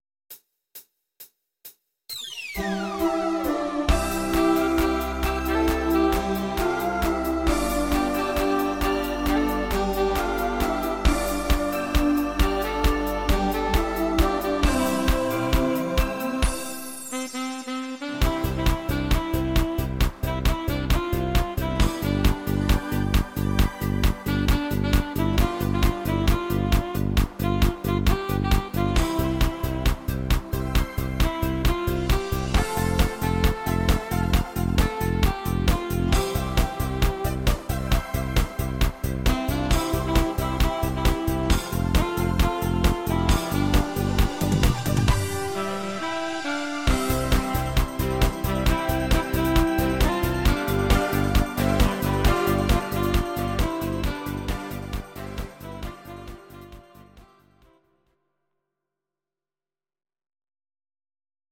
Audio Recordings based on Midi-files
German, Duets, 2010s